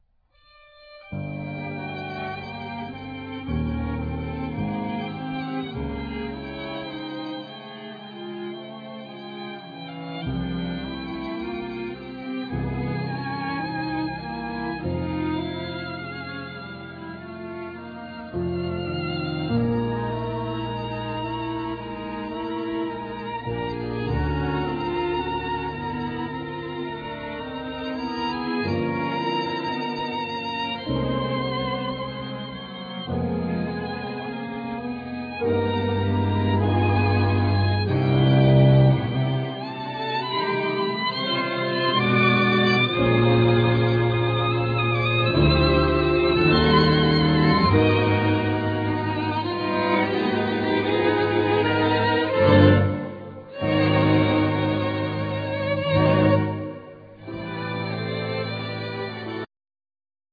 Piano
1st Violin
2nd Violin
Cello
Viola